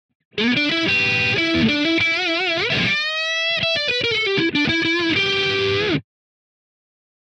YAMAHA Pacificaです。こちらはフロント、リアともにハムバッカーですね。
こちらはストラトのブリッジに変えてみました。音もシングルコイルな少しシャキっとした音になります。
PasificaNeckHum-StratoBridge.wav